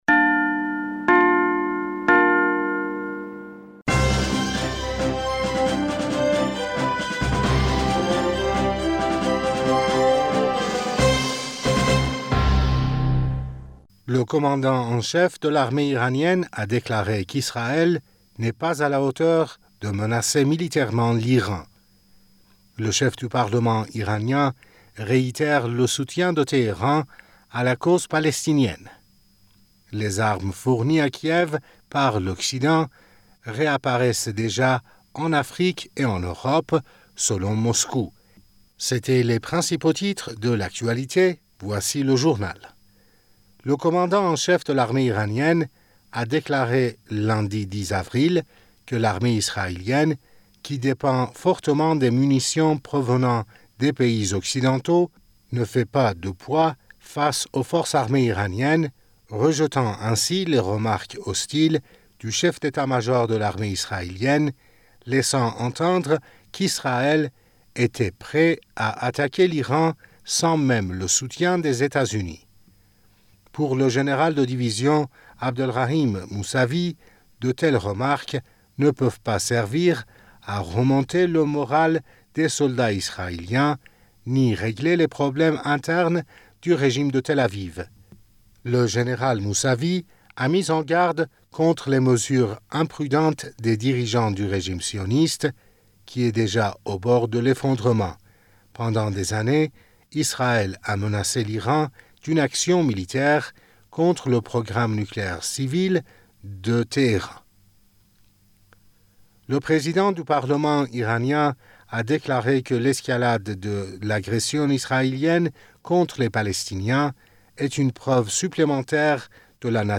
Bulletin d'information du 11 Avril 2023